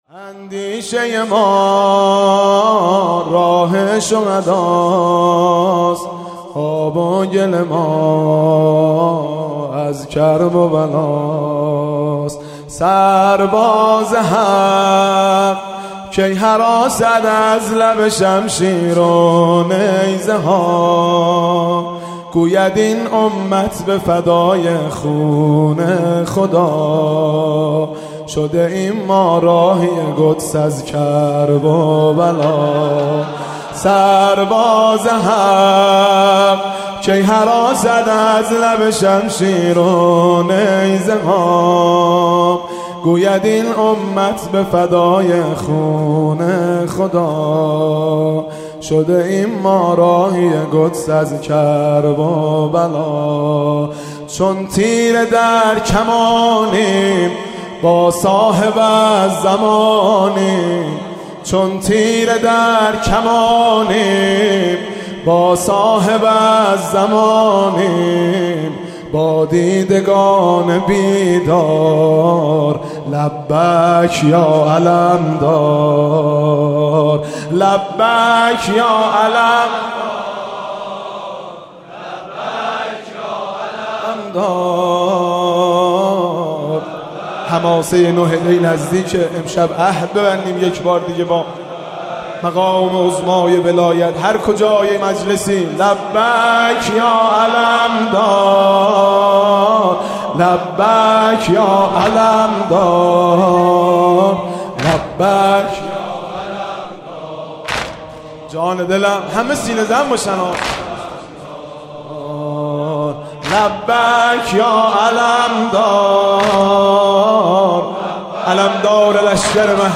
مداحی -مهدی رسولی- ۹ دی.mp3